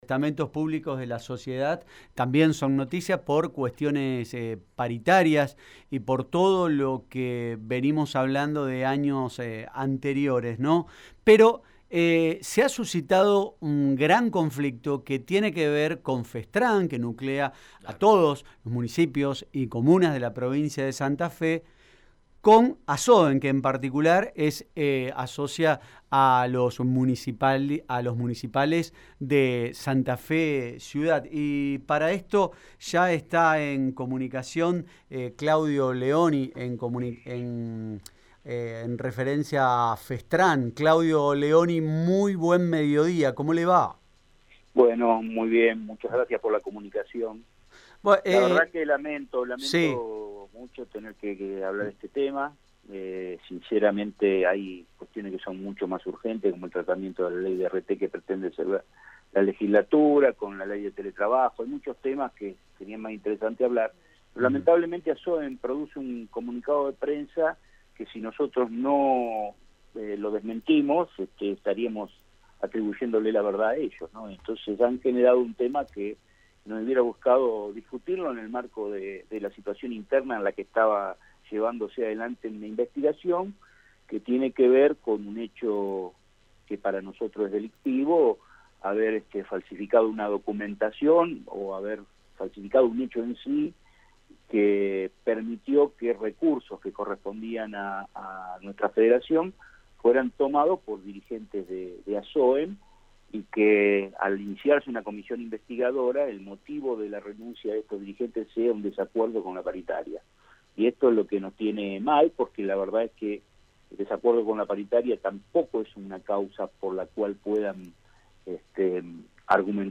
En dialogo con Radio EME